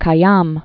(kī-yäm, -ăm), Omar